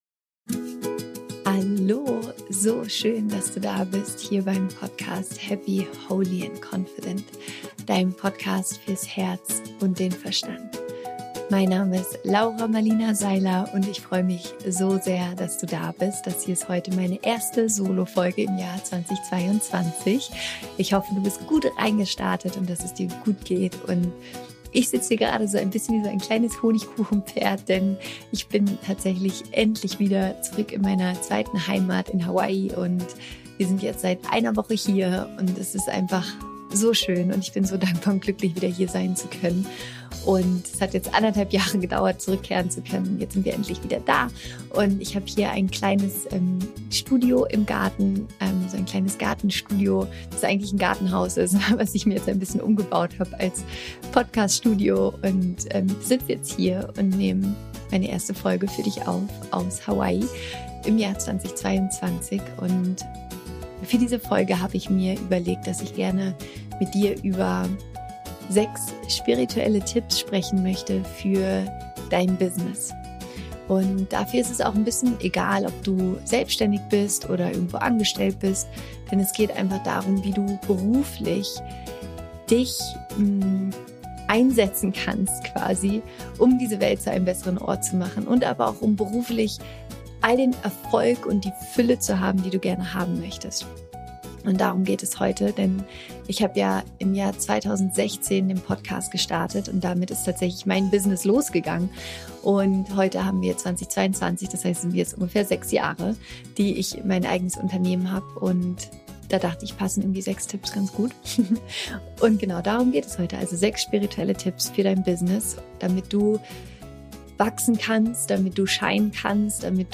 Ich freue mich so sehr, heute die erste neue Solofolge aus Maui mit dir zu teilen, denn nach über 1,5 Jahren bin ich endlich wieder zurück im wunderschönen Hawaii, auf Maui, meiner zweiten Heimat.